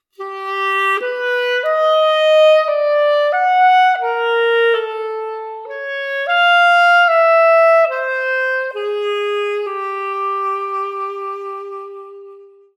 Here’s a simple unremarkable example of personal surprise experienced first hand:   I began playing the horn last night without any expectation, with only one paltry idea in hand.  It was a twelve-tone row I composed 40 years ago.
12 TONE ROW
twelve-tone-row.mp3